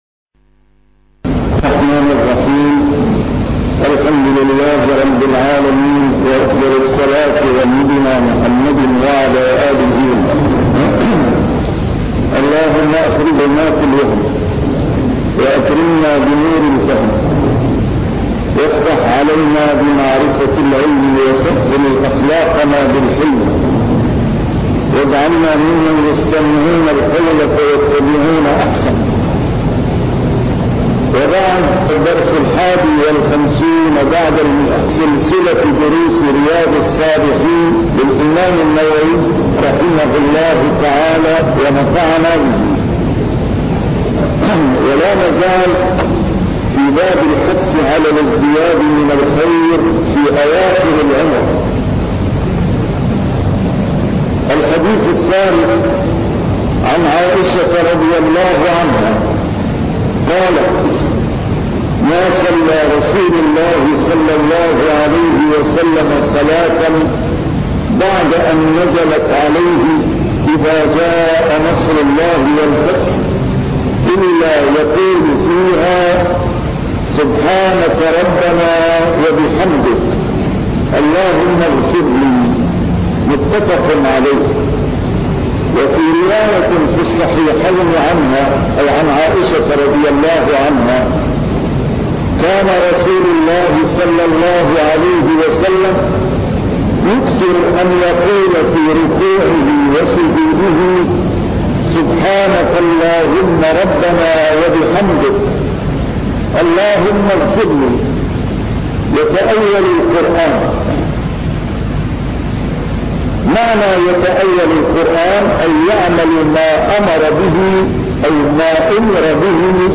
A MARTYR SCHOLAR: IMAM MUHAMMAD SAEED RAMADAN AL-BOUTI - الدروس العلمية - شرح كتاب رياض الصالحين - 151- شرح رياض الصالحين: الازدياد من الخير